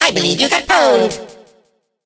evil_mortis_kill_vo_04.ogg